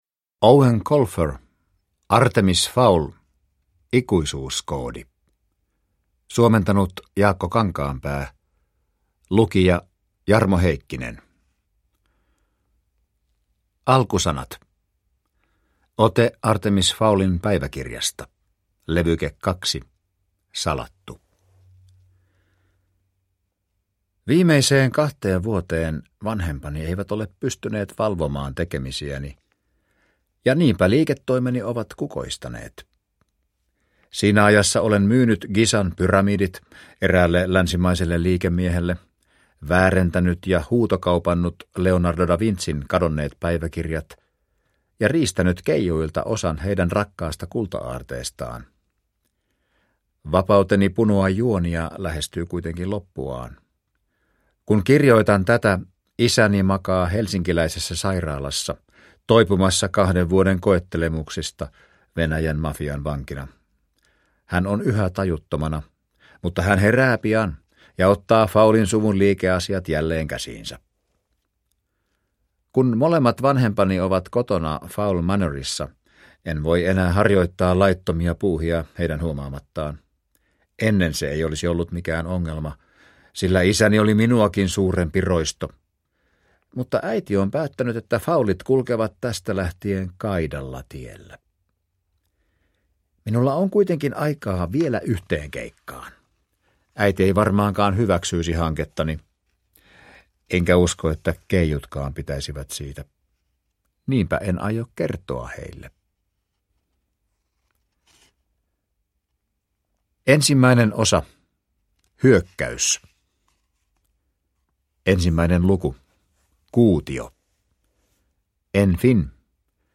Artemis Fowl: Ikuisuuskoodi – Ljudbok – Laddas ner